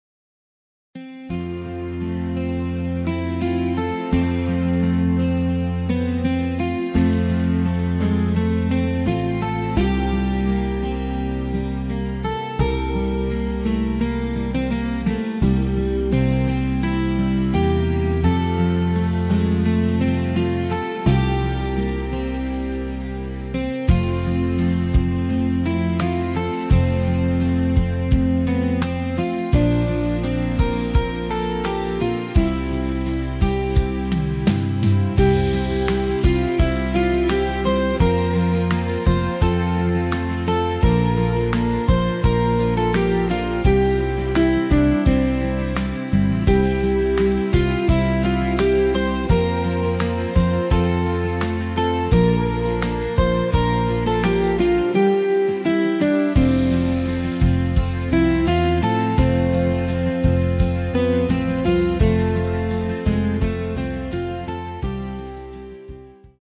Warmer, ambient Style
Musik
Ein ruhiger Rhythmus, der auch zum Träumen einlädt.